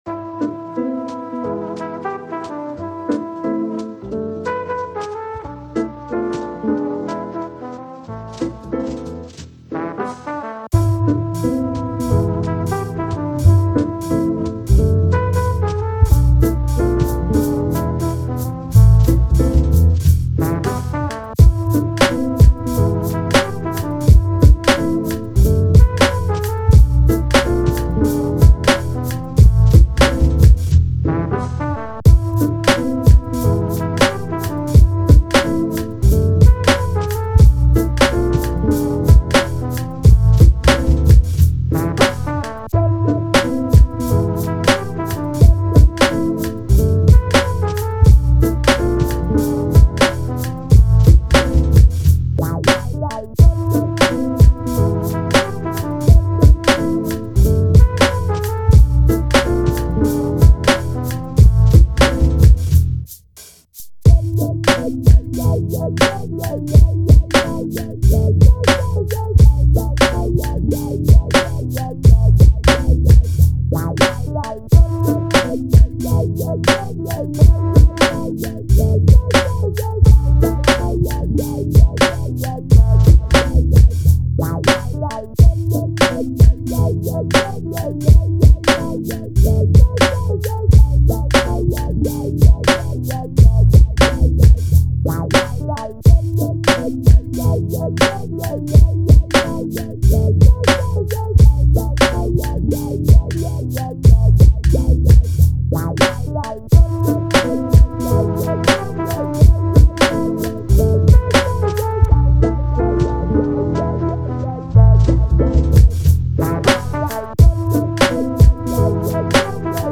Ill new beat-tape